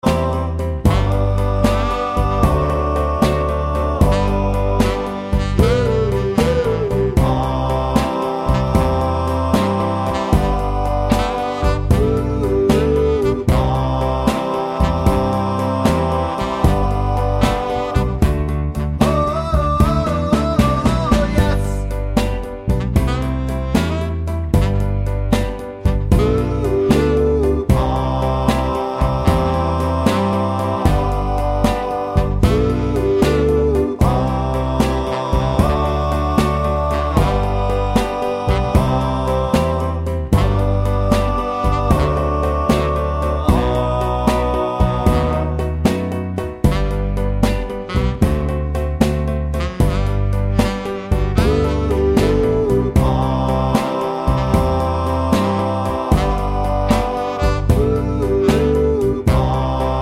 no Backing Vocals Soul / Motown 2:41 Buy £1.50